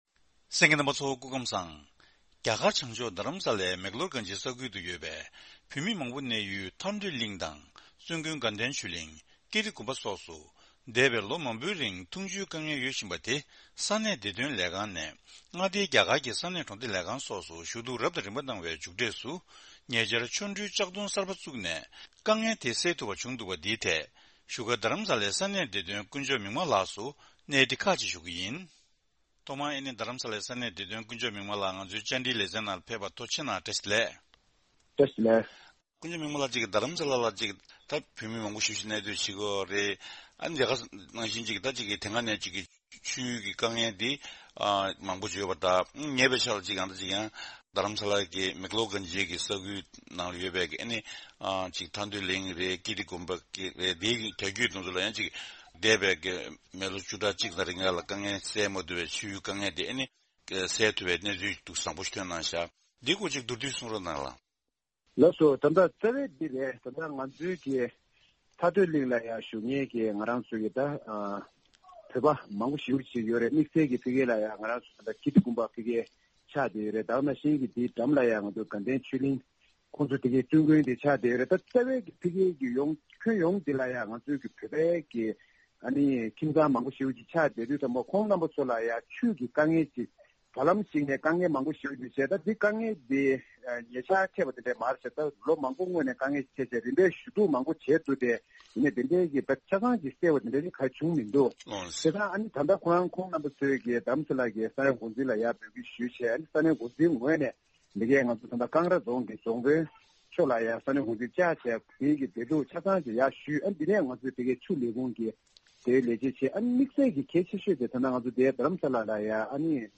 དེ་རིང་གི་གནས་འདྲིའི་ལེ་ཚན་ནང་།